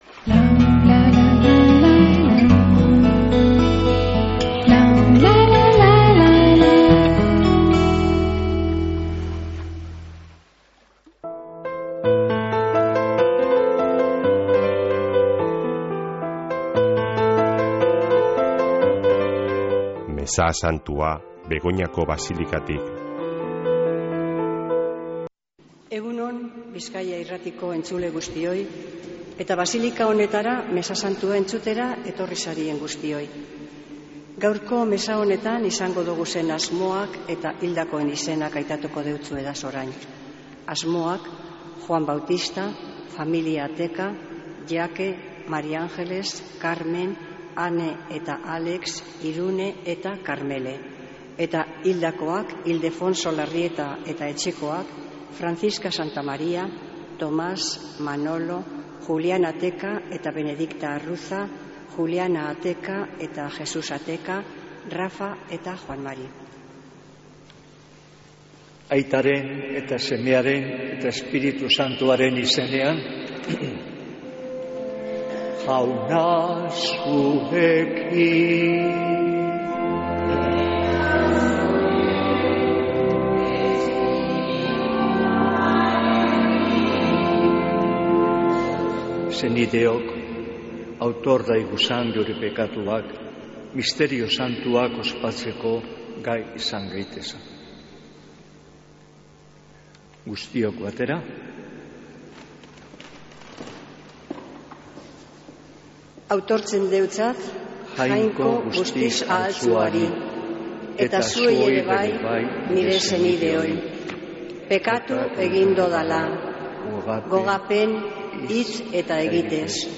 Mezea Begoñako basilikatik | Bizkaia Irratia
Mezea zuzenean Begoñatik